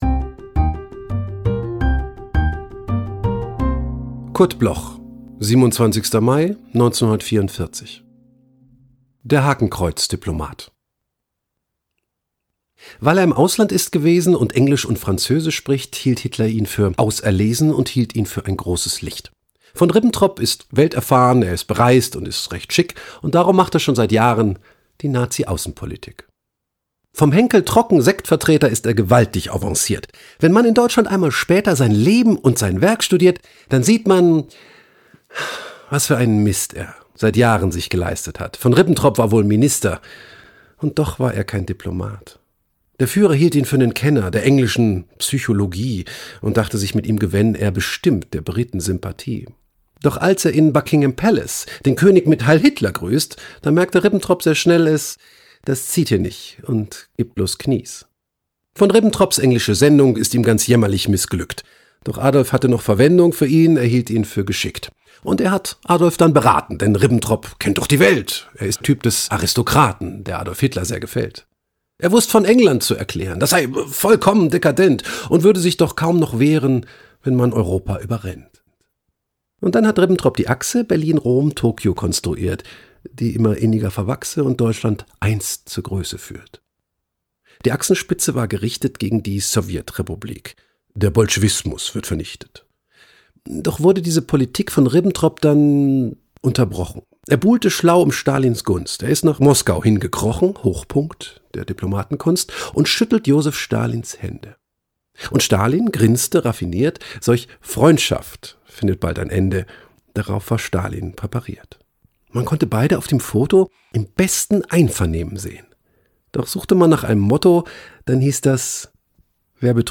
Claus von Wagner (* 1977) is een Duitse cabaretier.
Claus-von-Wagner_HAKENKREUZDIPOLMAT_mit-Musik.m4a